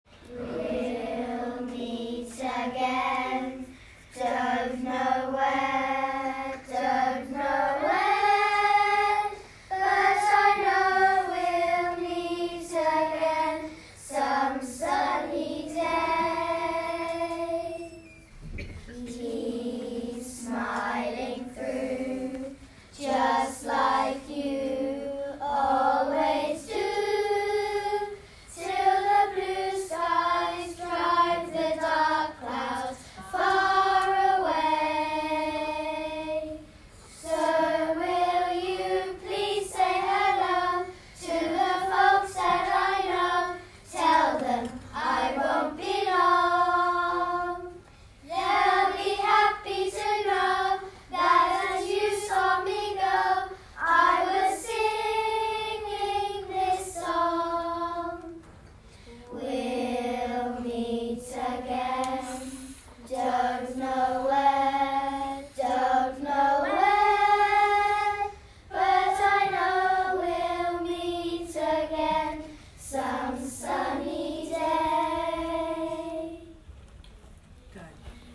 On Monday 23rd May, the pupils of Lambourn Church of England Primary School entertained visitors to the school in song.